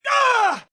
Screaming Dedman 4